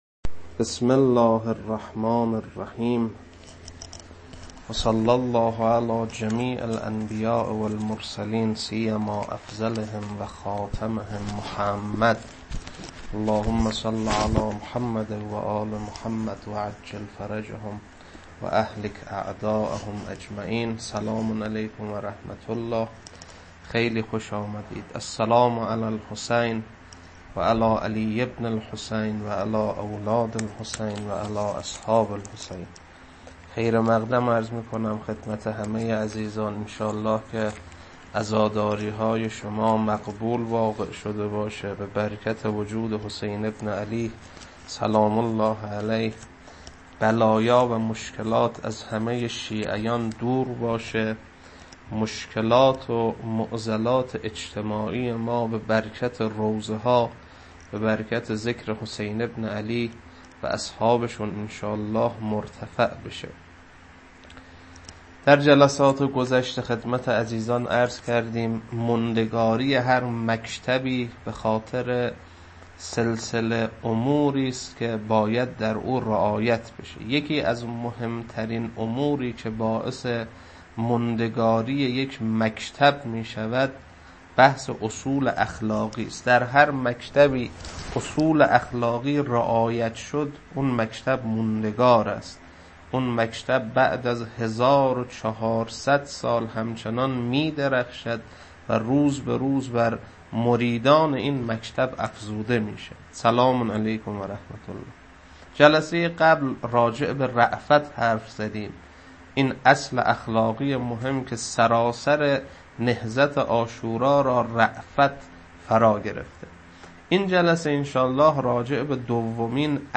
روضه شب نهم محرم 1400.mp3
روضه-شب-نهم-محرم-1400.mp3